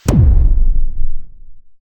explodeLarge.ogg